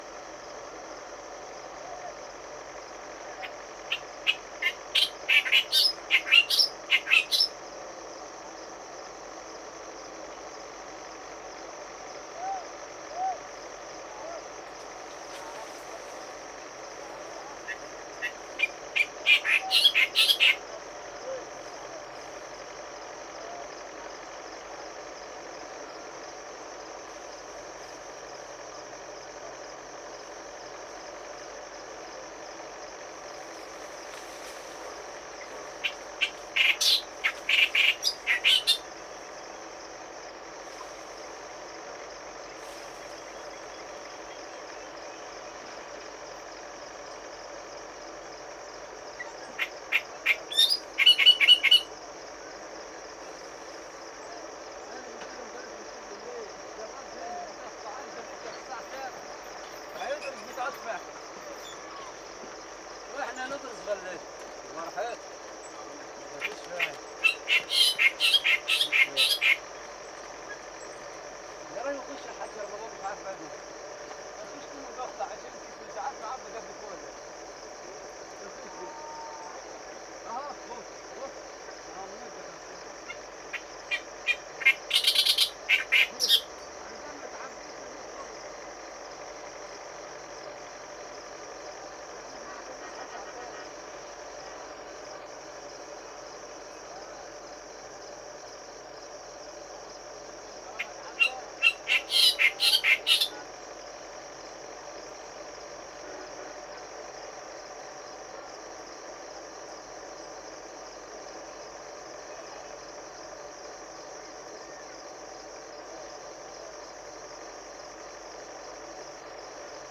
In April and May 2012 I stayed in Lower Egypt for a bird migration survey.
Another common species in Lower Egypt is the Clamorous Reed Warbler
It’ s song is audible over good distance, so you
120516, Clamorous Reed Warbler Acrocephalus stentoreus, Sandafa al Far, Egypt